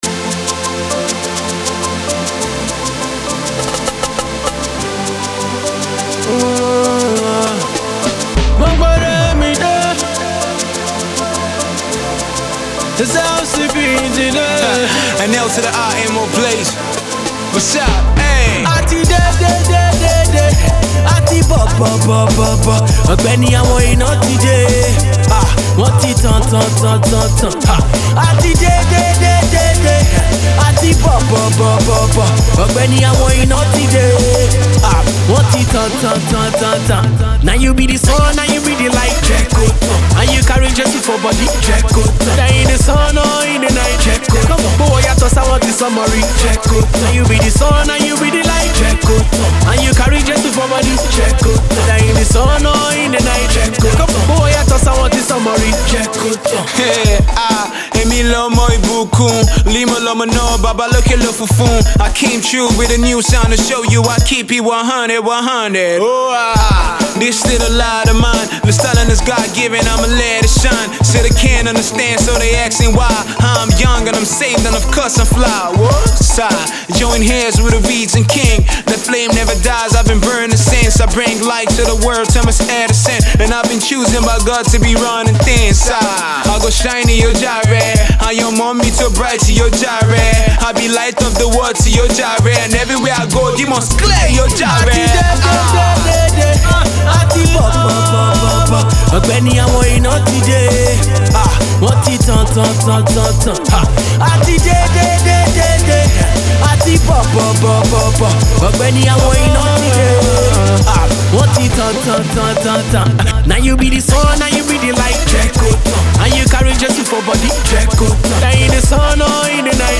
features a passionate and intoxicating performance
rapper/singer